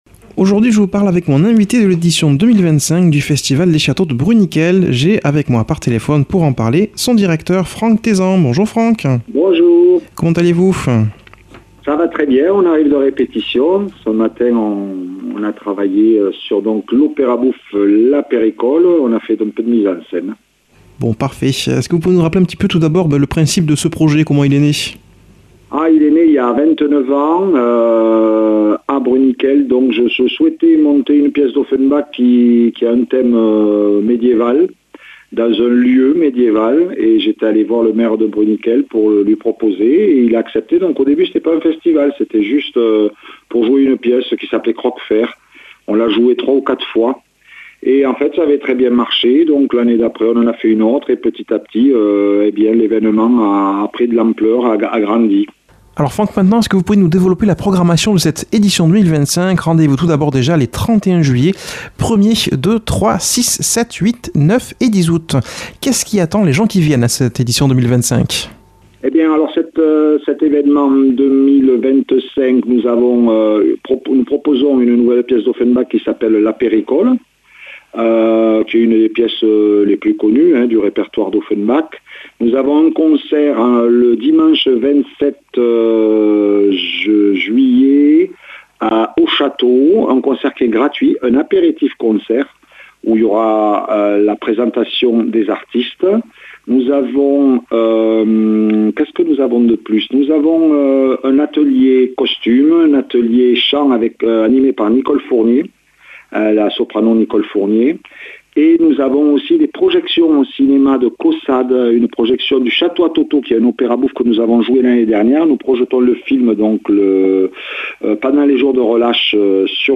a comme invité par téléphone